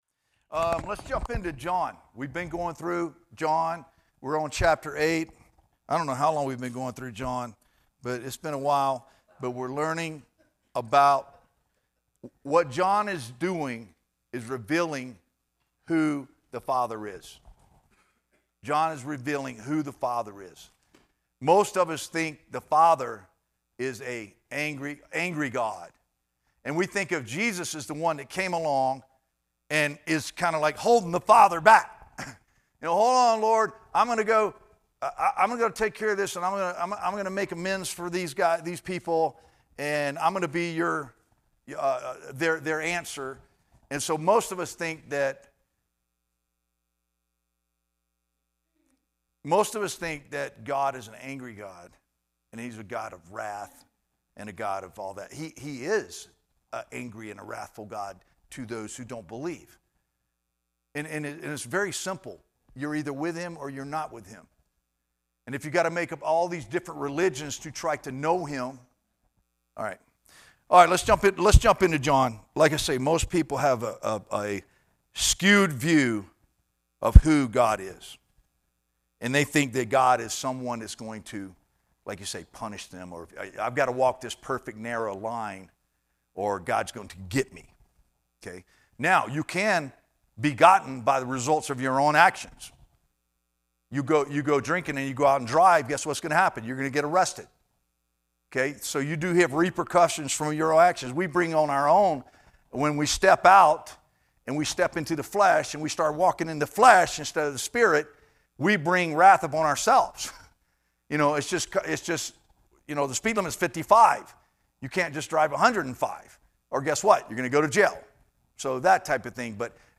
teaches a lesson